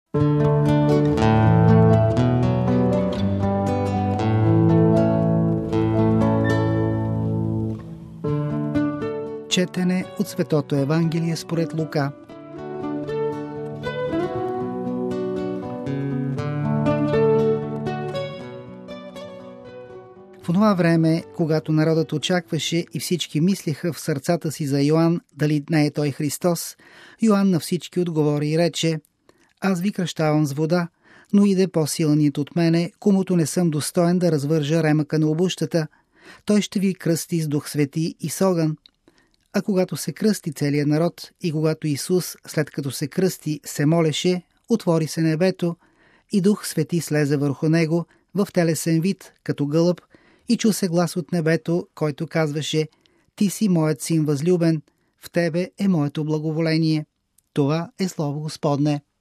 Проповед